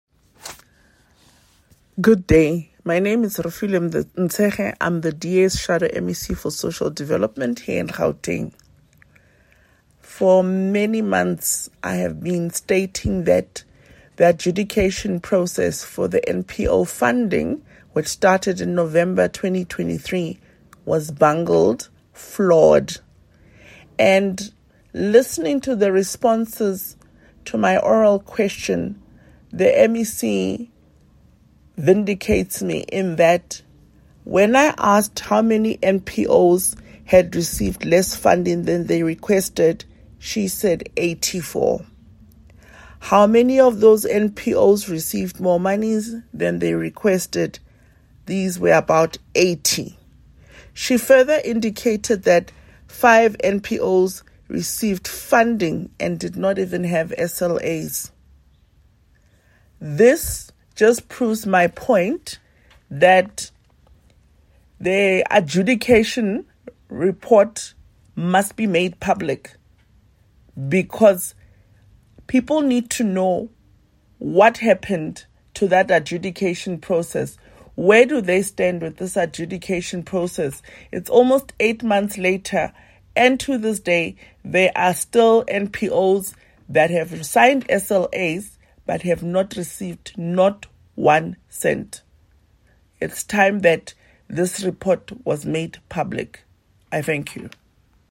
here from DA MPL, Refiloe Nt’sekhe.